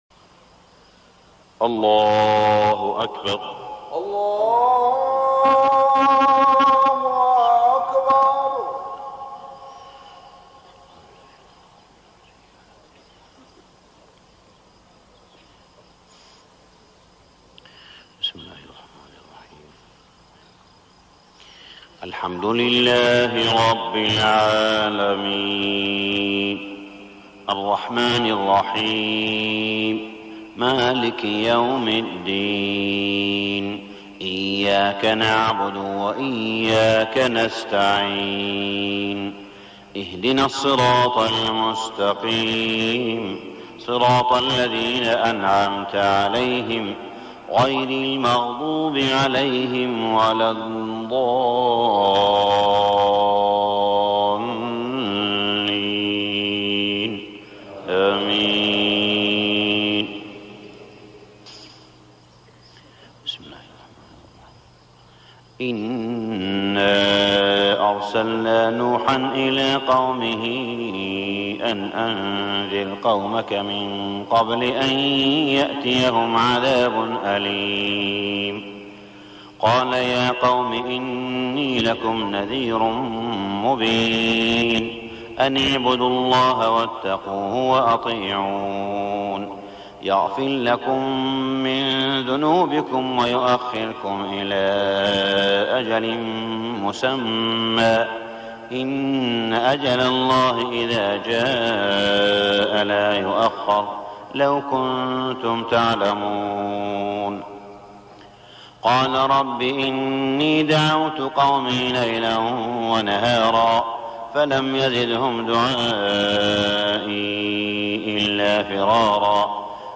صلاة الفجر 1421هـ سورتي نوح و القيامة > 1421 🕋 > الفروض - تلاوات الحرمين